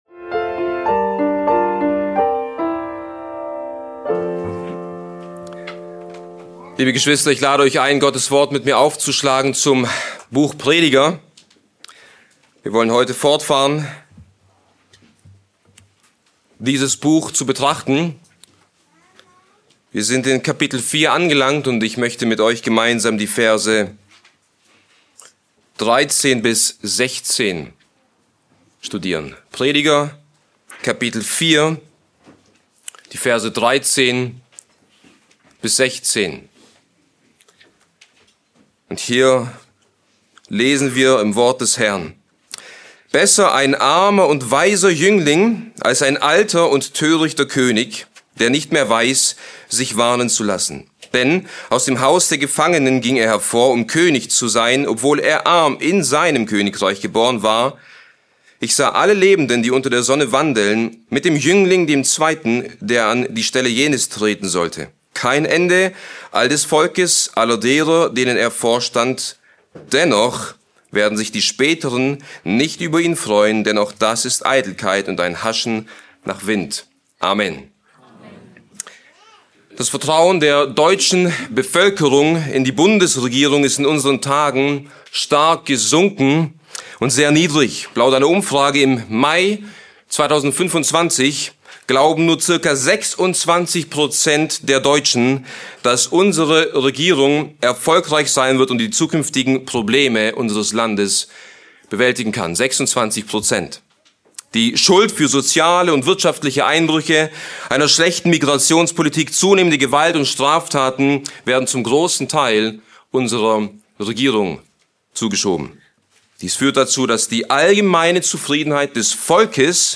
Bibeltreue Predigten der Evangelisch-Baptistischen Christusgemeinde Podcast